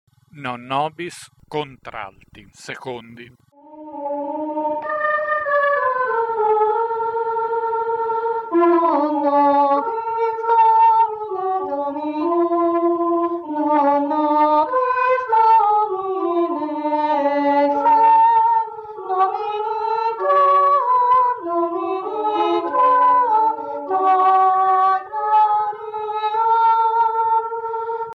Non nobis - Contralti 2 + Base.mp3